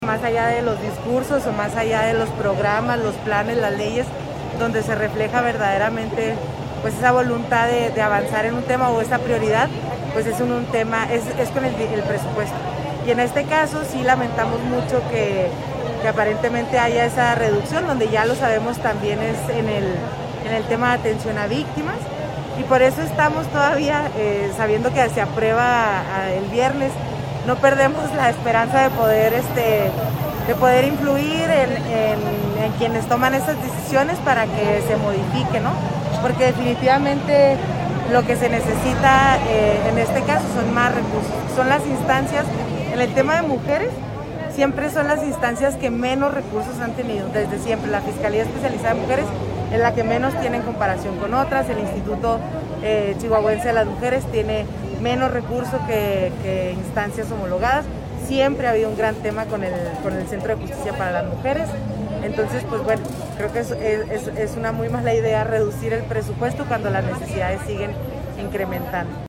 Lo anterior durante la conmemoración que diversos grupos realizaron este jueves por los 11 años del asesinato de la activista Maricela Escobedo, al exterior de Palacio de Gobierno en la placa en su honor.